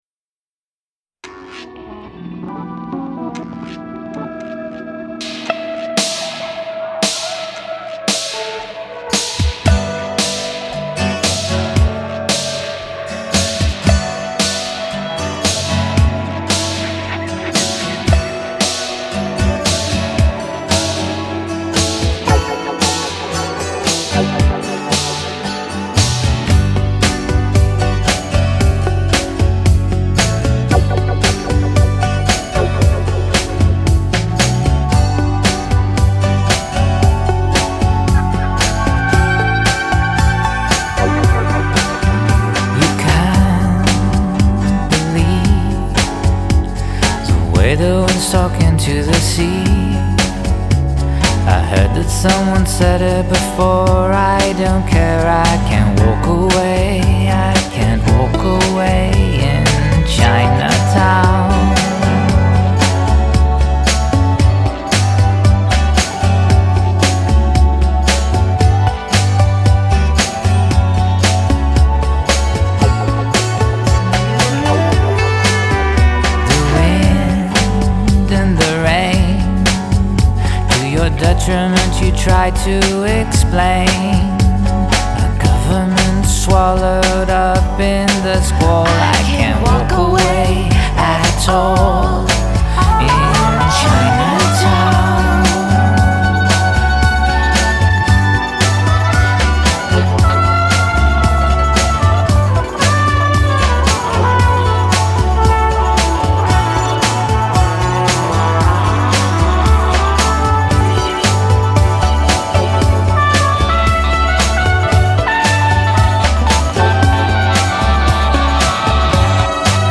You know, like soft rock.